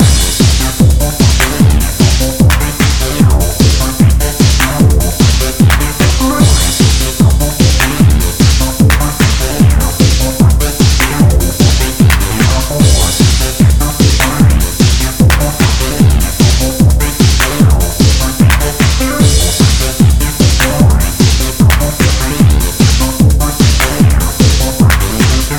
TR-909っぽいフリーのVSTってないかな～と思って探していたらMARVIN PAVILIONと言うサイト
で、鳴らしてみるとソレっぽい音がしてかなり良い感じ＾＾
ベースには同じサイトで配布されていたSynthestraを使用しています。
ベース用の音源って訳でもないのですが、結構ビヨビヨした音が出て良い感じ。
後、エフェクトにリバーブとコンプレッサーとマキシマイザを使ってます。